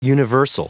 Prononciation du mot universal en anglais (fichier audio)
Prononciation du mot : universal